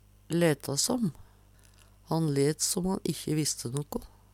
leta som - Numedalsmål (en-US)